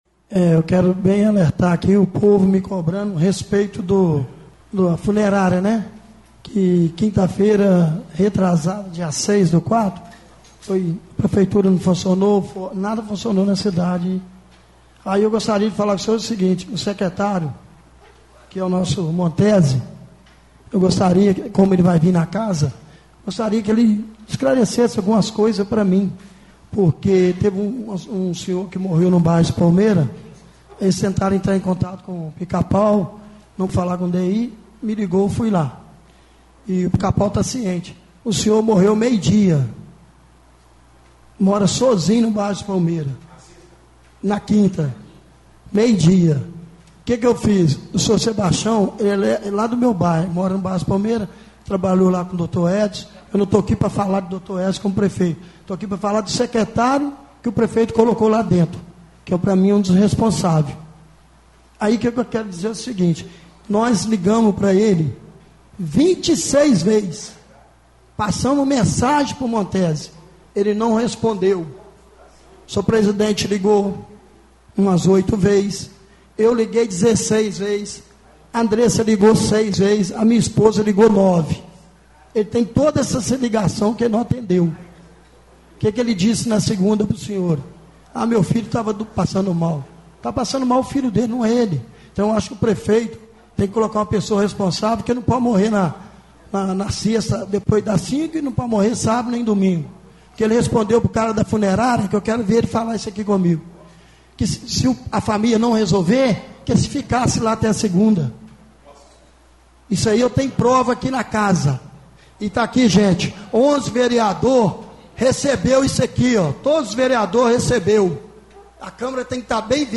Áudio extraído da sessão ordinária dessa segunda-feira,17.